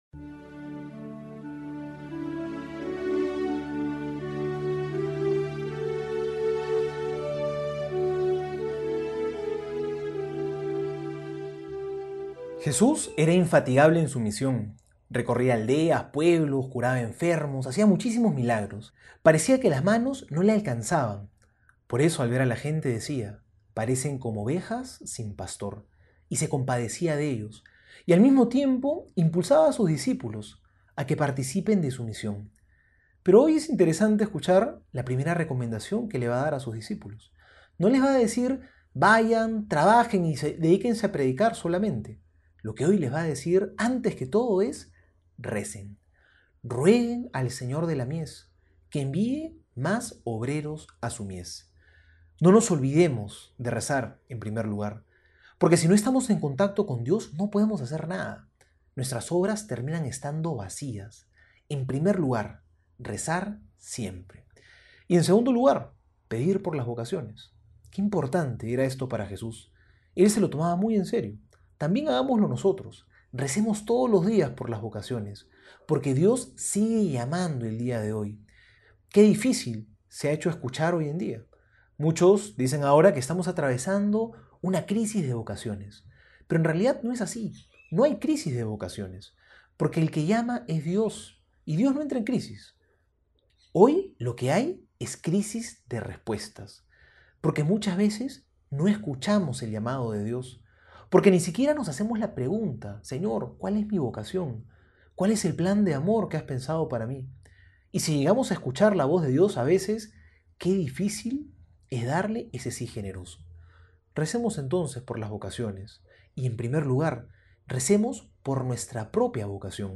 Homilía para hoy:
Martes Homilia Mateo 9 32-38.mp3